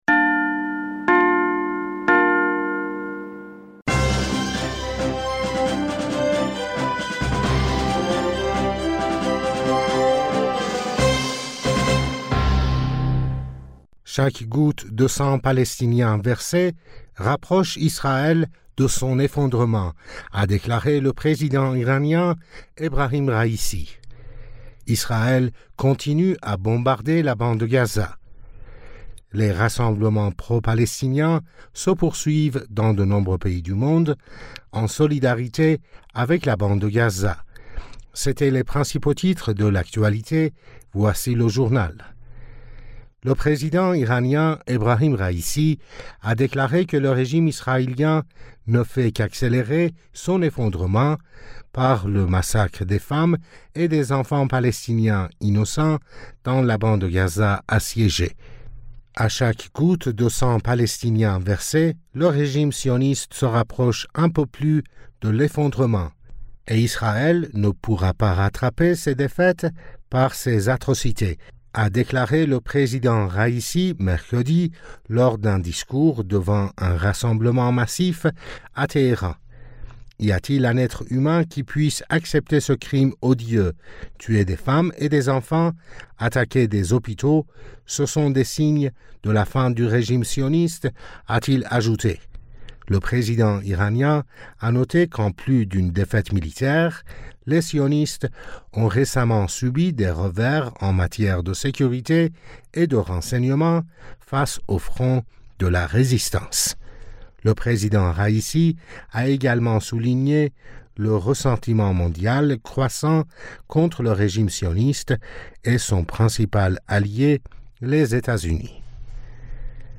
Bulletin d'information du 19 Octobre 2023